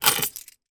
На этой странице собраны различные звуки наручников: от звонкого удара металла до характерного щелчка замка.
Звук снятия наручников голыми руками